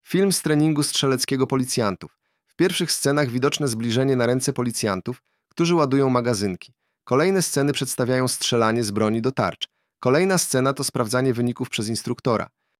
Nagranie audio Audiodeskrypcja